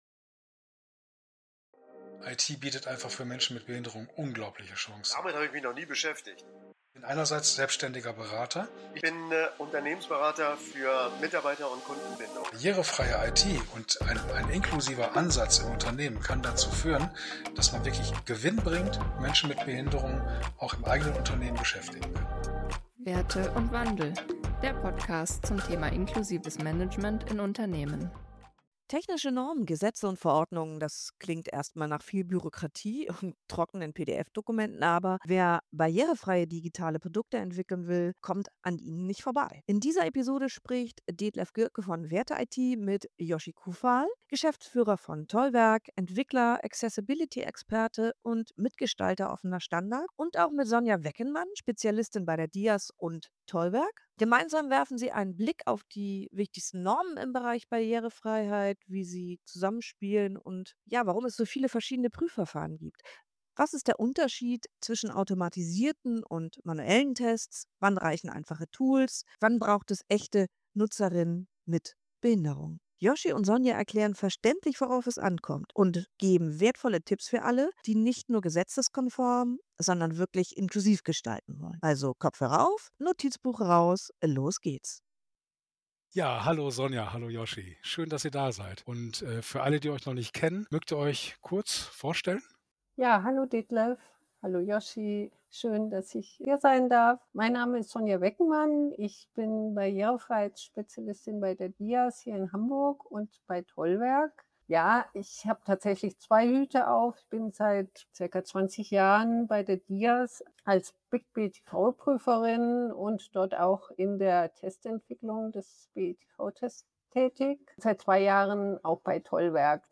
In dieser Diskussion wird die Rolle automatisierter Tests in der Barrierefreiheit beleuchtet. Es wird festgestellt, dass automatisierte Tests nur einen Teil der Mängel identifizieren können, während der Großteil manuelle Überprüfungen erfordert.